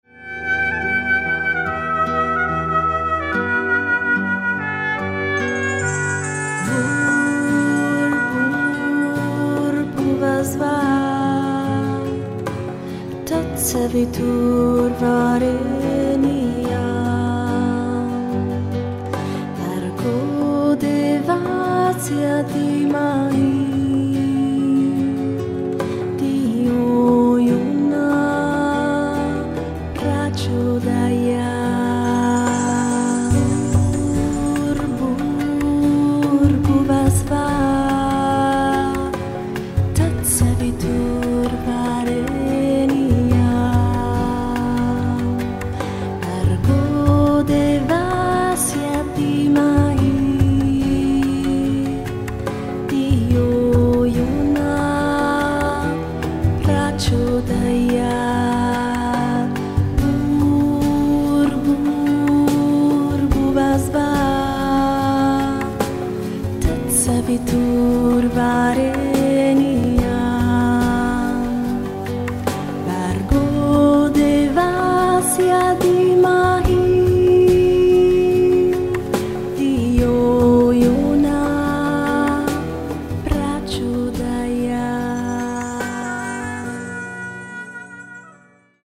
mantra 432 Hz, meditazione
relax